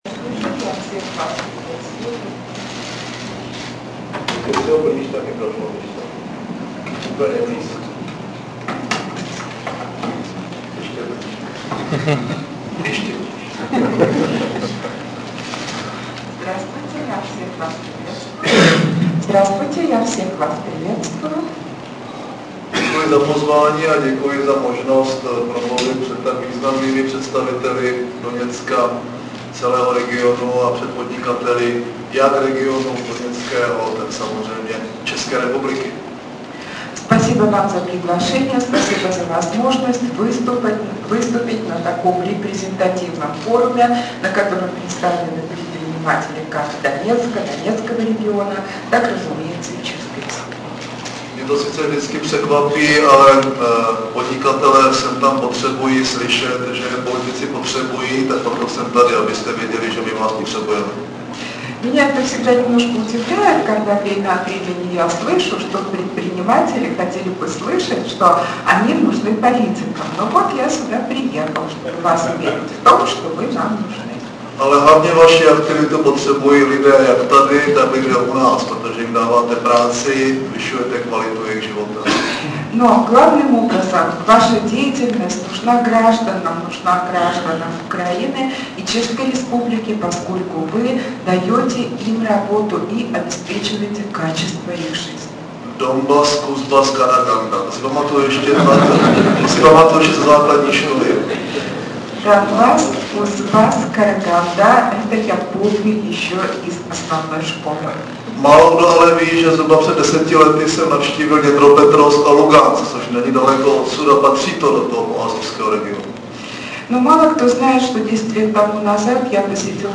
Komentář předsedy vlády ČR M. Topolánka ke slavnostnímu otevření Českého domu v Doněcku Zvukový záznam mp3 • 1283 kB Doněcko - premiér na podnikatelském fóru zvukový záznam vystoupení premiéra M. Topolánka mp3 • 6993 kB